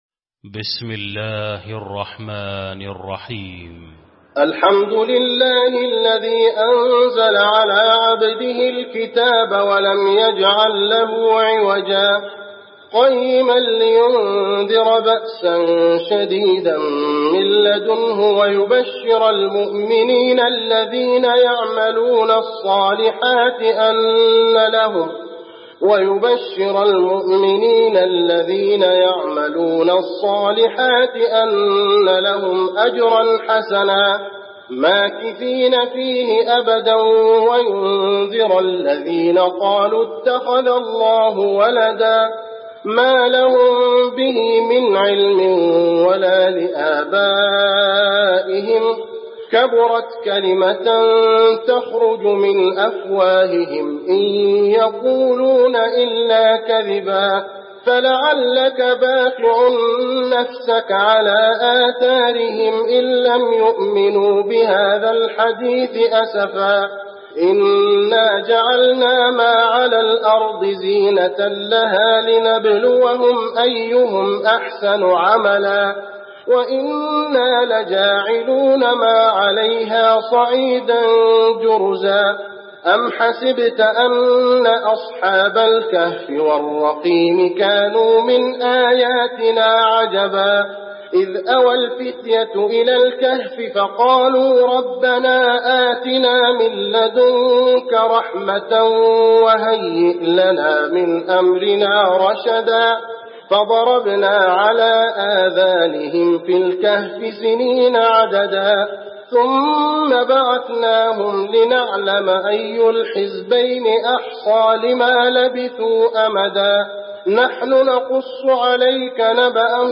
المكان: المسجد النبوي الكهف